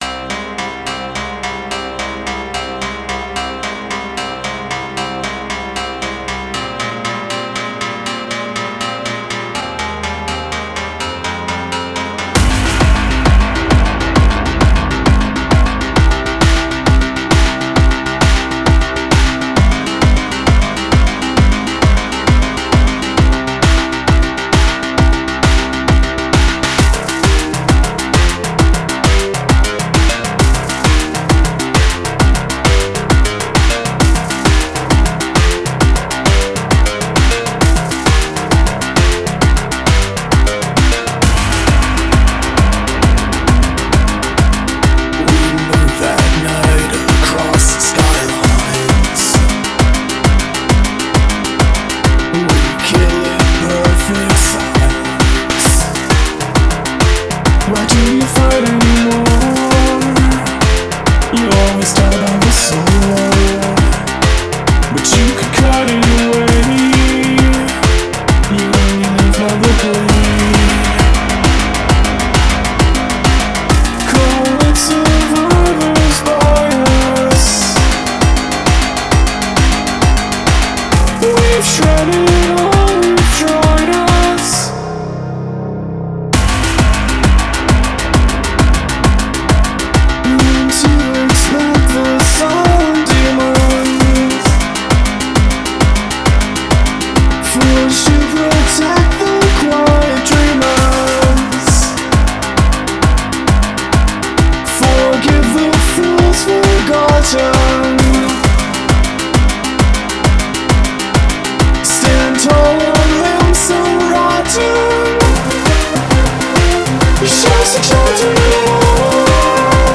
EBM and Industrial net radio show